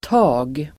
Uttal: [ta:g]